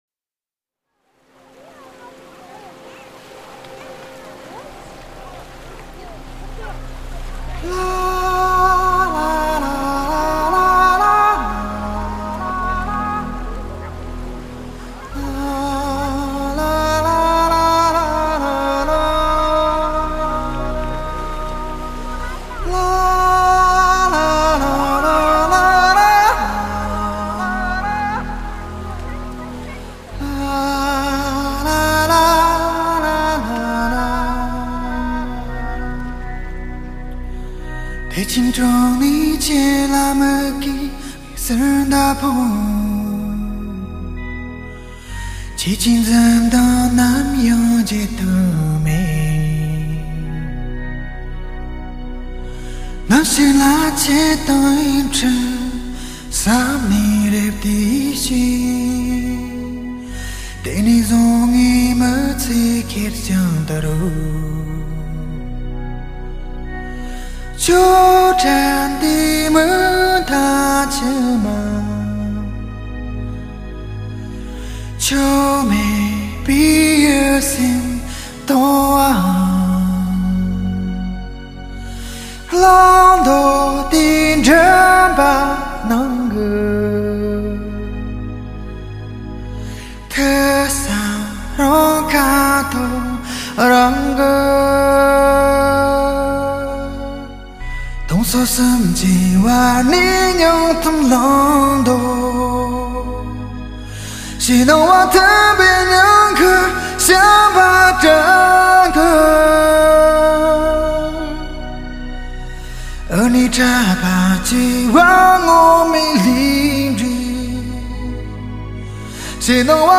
高亢辽阔  絮语萦回  诠释至情至性的高原情歌
释放蒙藏的古朴神秘  糅合时尚多元的音乐元素